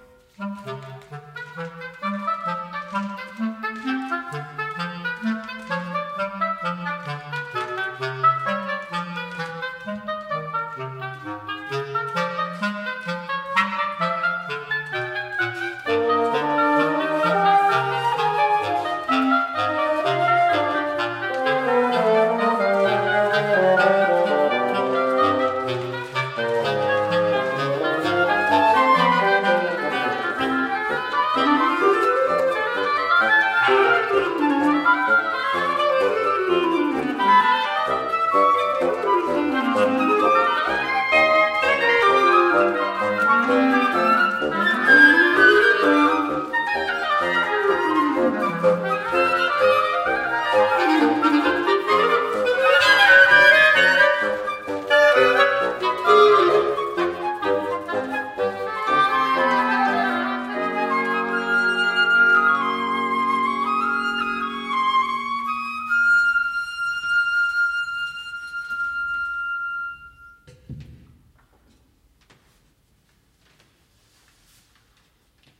hobo
dwarsfluit
fagot
basklarinet